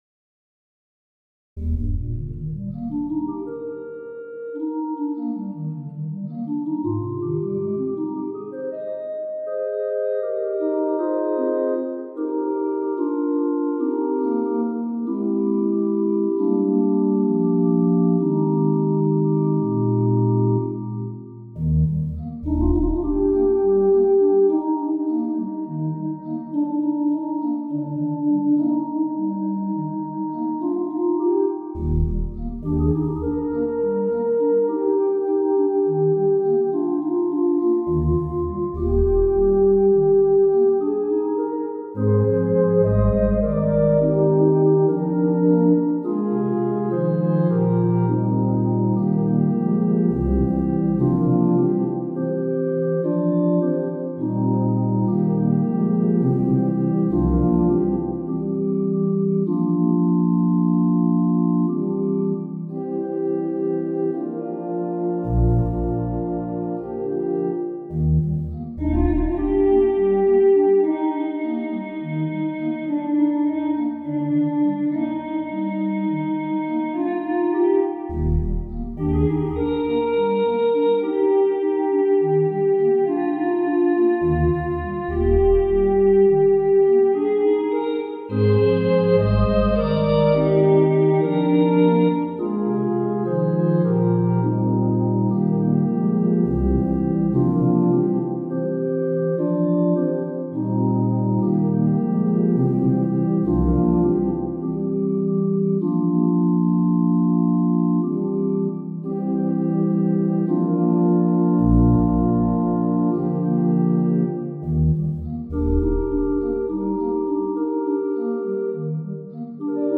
for organ Arpeggios in 7/8 meter, phrased as 3+2+2, accompany a simple melody, with repeats for a change in registration.